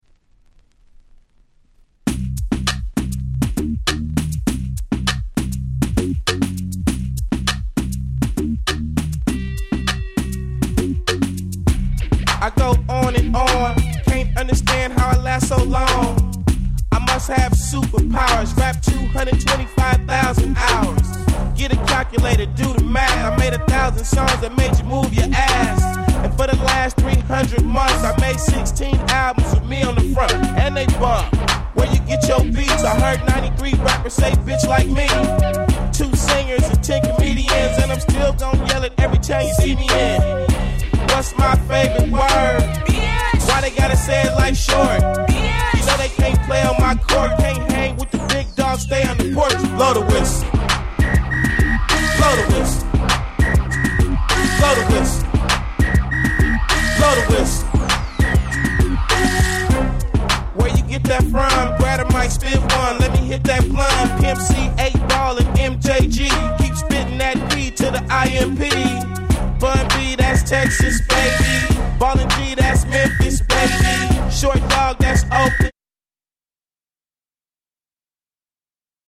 『Hyphy』のブームを作った最重要曲！！